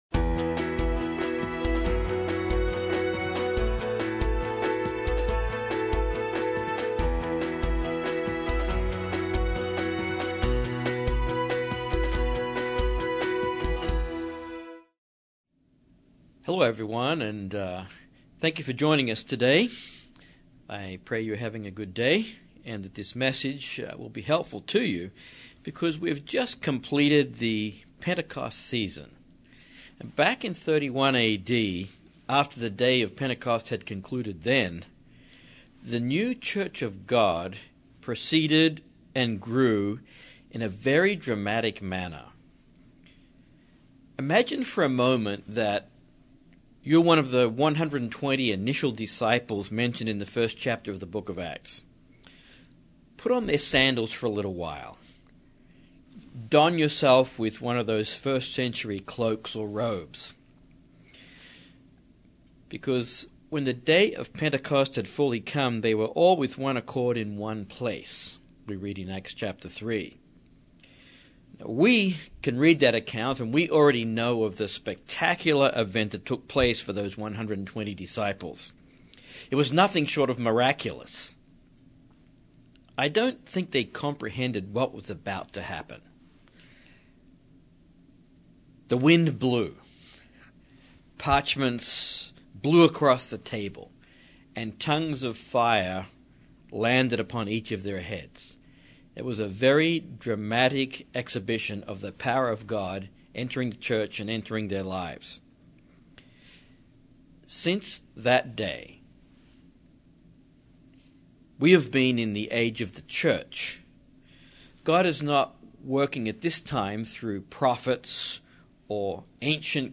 In this sermon we cover two main thoughts: what was God’s purpose for the early New Testament Church and, by extension, what is God’s purpose for you and me today?